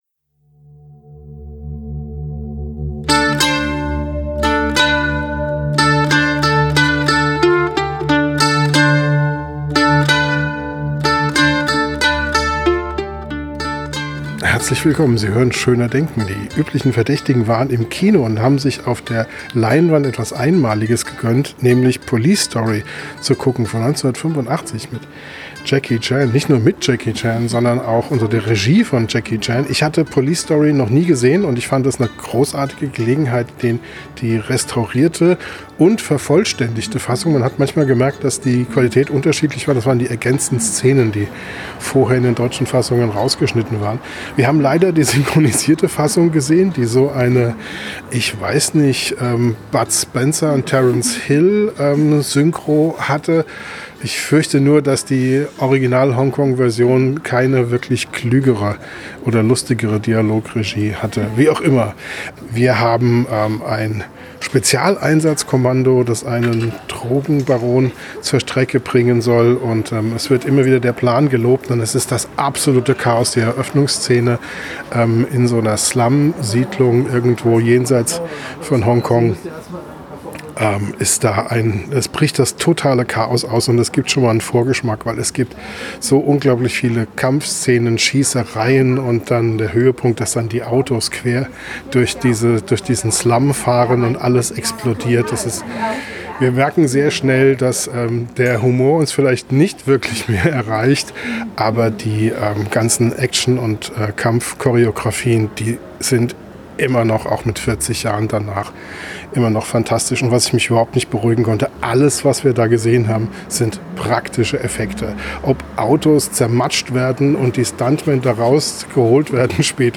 Am Mikrofon direkt nach dem Film